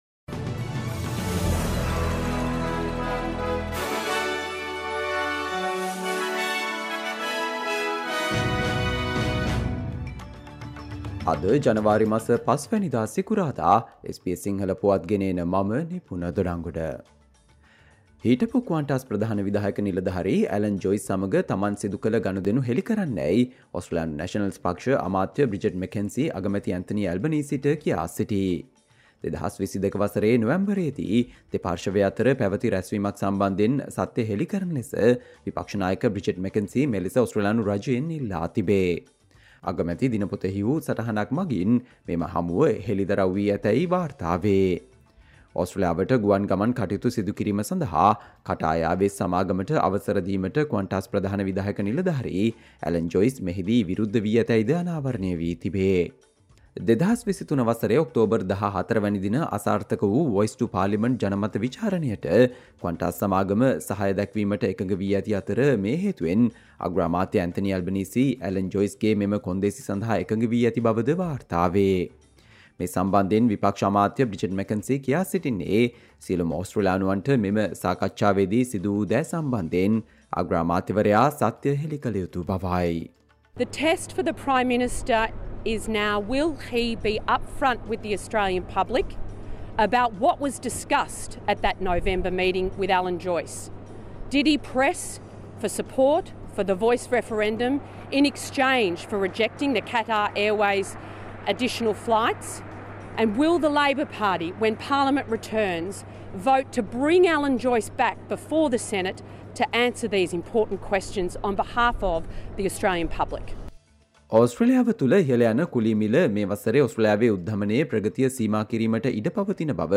Australia news in Sinhala, foreign and sports news in brief - listen, Friday 05 January 2024 SBS Sinhala Radio News Flash